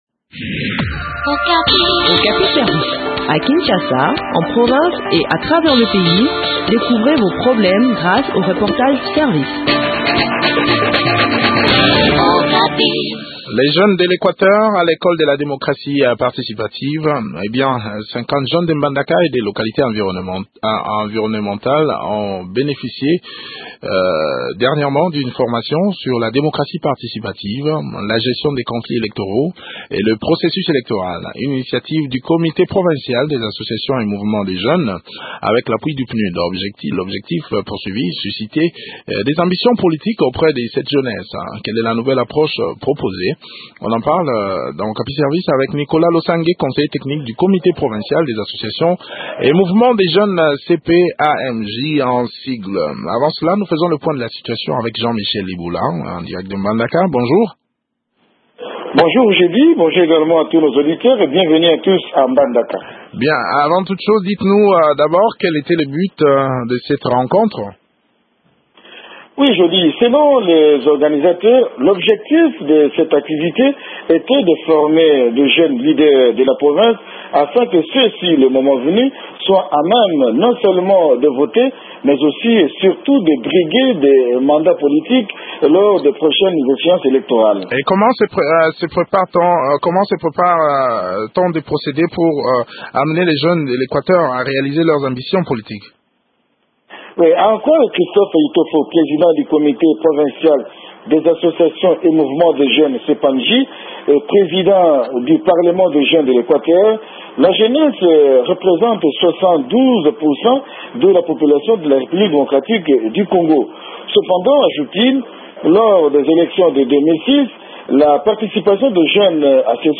Le point sur le sujet dans cet entretien